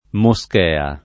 moschea [ mo S k $ a ] s. f.